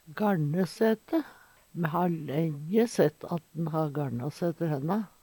Ln - lyden er ikkje rein Ln lyd, ly til lydfil